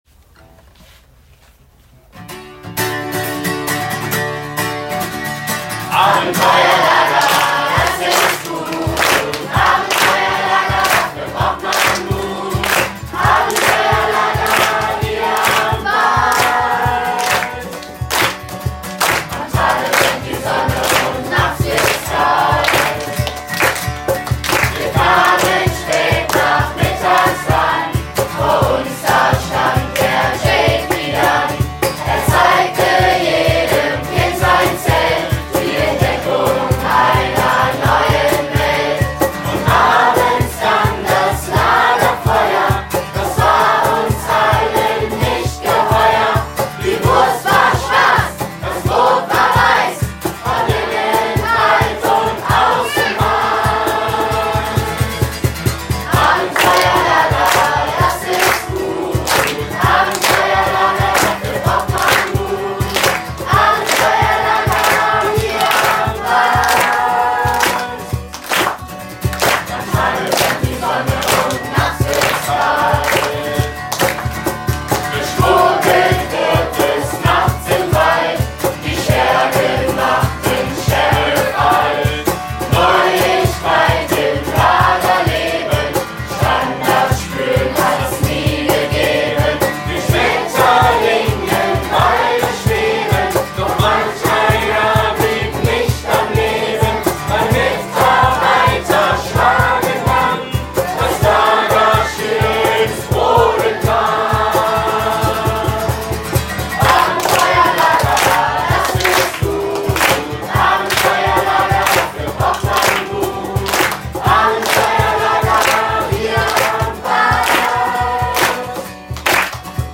Jeden Tag unseres Abenteuercamps wird eine neue Strophe gedichtet, in der die Highlights des Tages besungen werden.